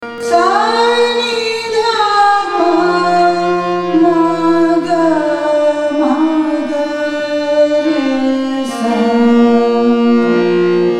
AvarohaS’ N D P M G m G R S